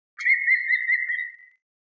Quando è posato sugli alberi non è facile scorgerlo, ma lui segnala la sua presenza con una specie di squillante
"risata" (86 KB).
picchioVerde.wav